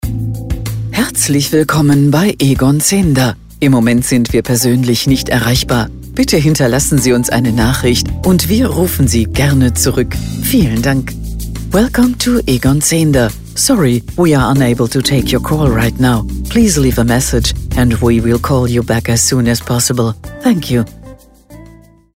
Anrufbeantworter Ansage: Außerhalb der Geschäftszeiten für Egon Zehnder